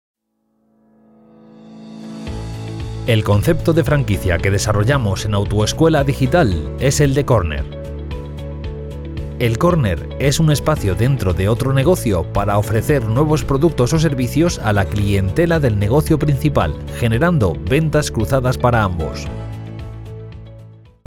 Spanish Castilian male Voice Over (Baritone) Warm and deep voice talent.
Sprechprobe: Werbung (Muttersprache):
Sweet voice, warm, deep, energetic ... very adaptable voice.